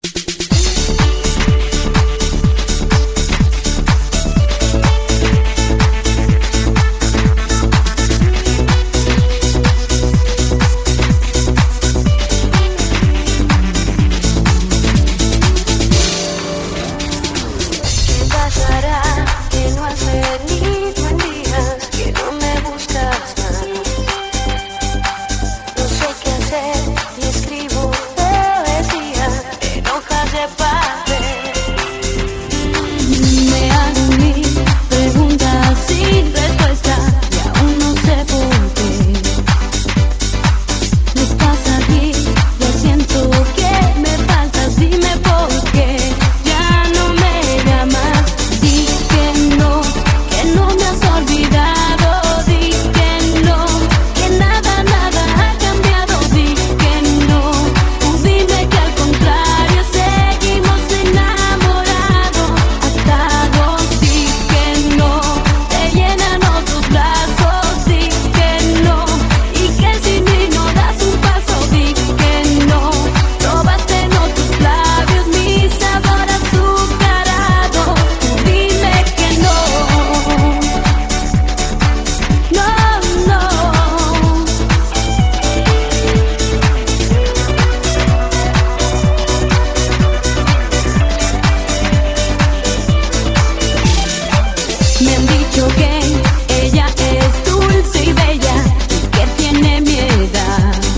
Listen to the Mix version of this song in Real Audio